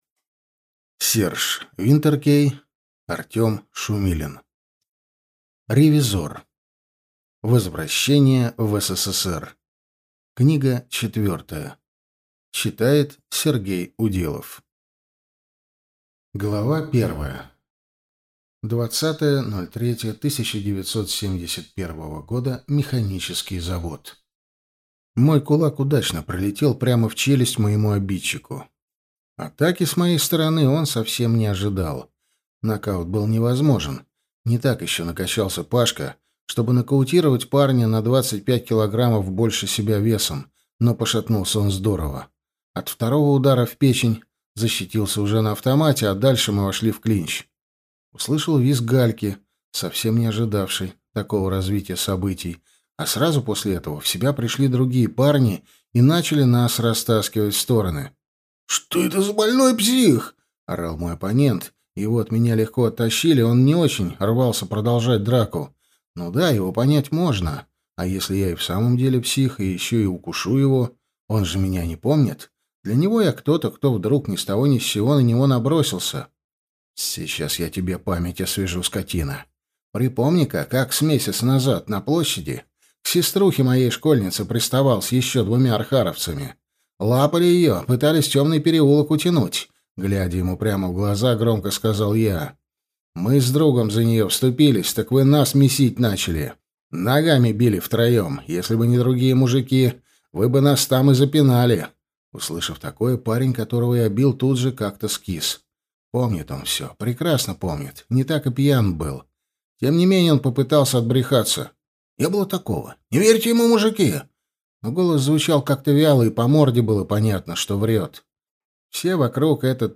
Аудиокнига Ревизор: возвращение в СССР 4 | Библиотека аудиокниг